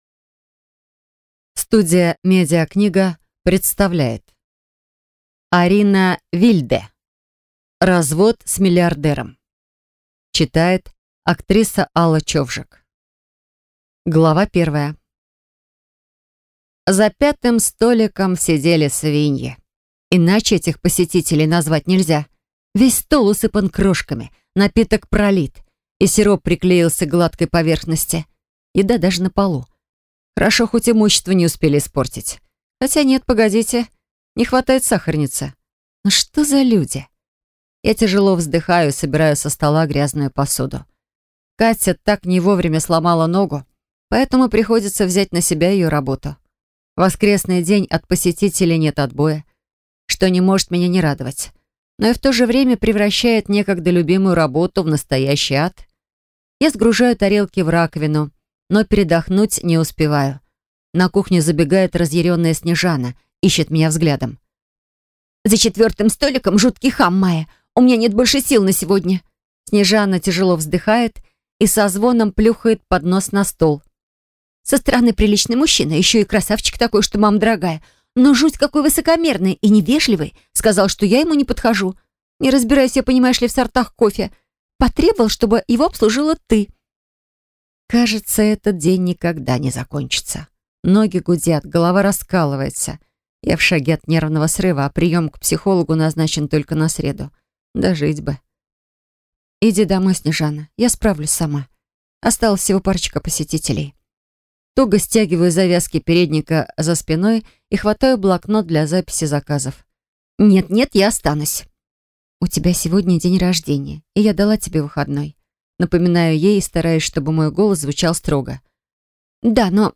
Аудиокнига Развод с миллиардером | Библиотека аудиокниг